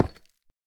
Minecraft Version Minecraft Version snapshot Latest Release | Latest Snapshot snapshot / assets / minecraft / sounds / block / deepslate / place1.ogg Compare With Compare With Latest Release | Latest Snapshot